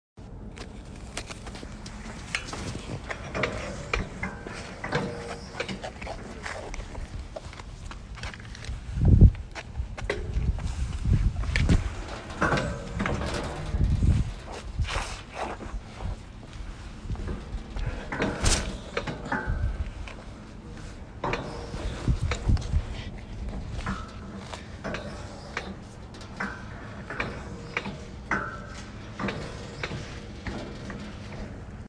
Location- Just outside Hague house at the very end of Netherlands North
Sounds in Clip- The clanking of the new gate and the sound of it orbiting and a tiny bit of my shoes on the ground